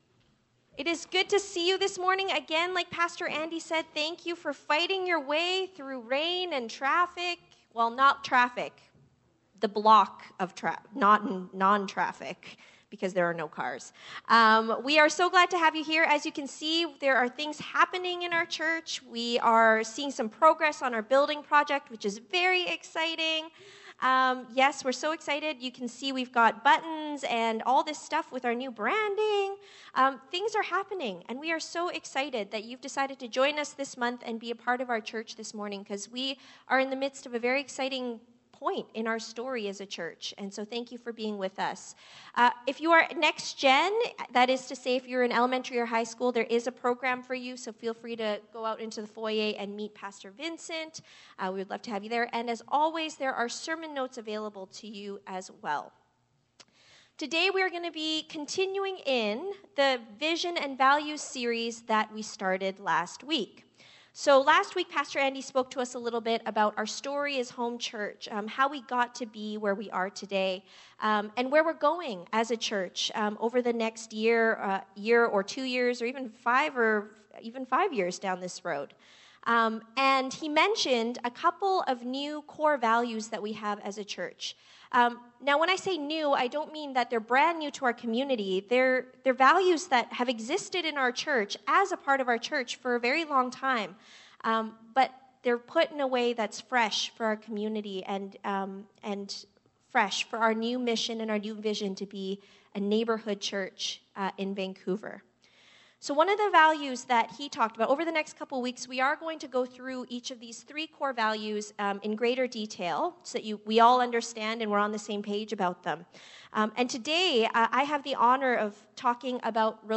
Matthew 22:34-40 Sermon